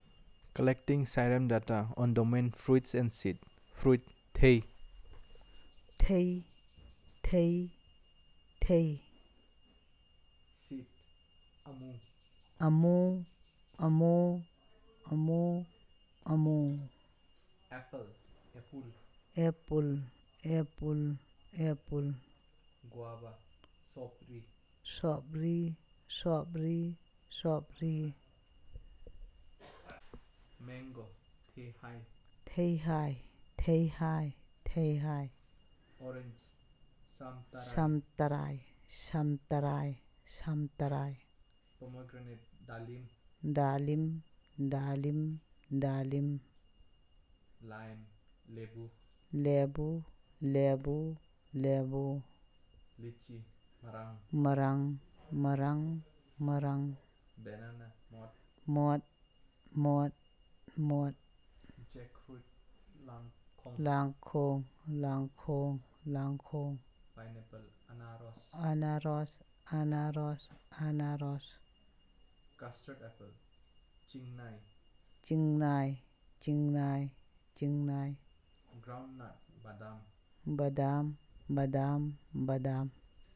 Elicitation of words about fruits and seeds